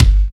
62 KICK 2.wav